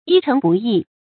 發音讀音